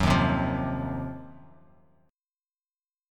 Ebm6add9 chord